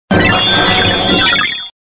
Cri d'Arceus dans Pokémon Diamant et Perle.